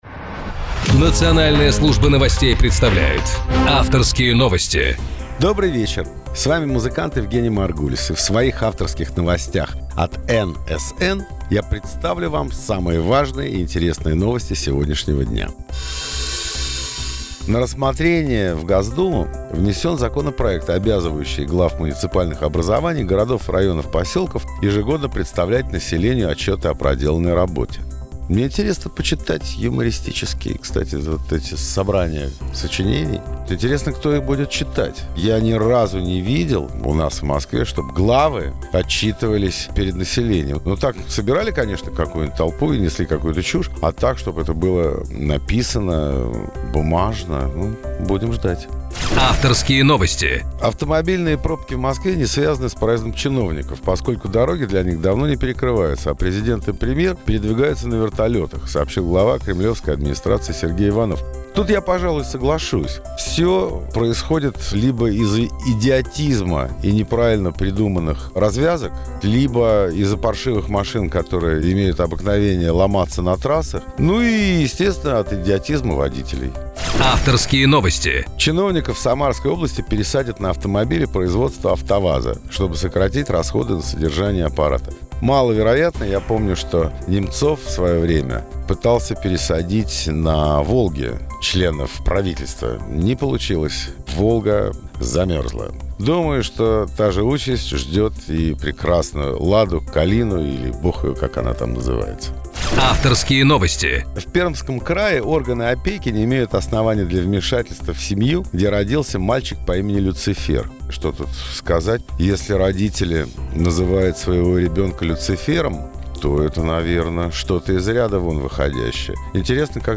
Евгений Маргулис 16.10.2014 на радио "Национальная служба новостей" НСН ФМ в передаче "Авторские новости"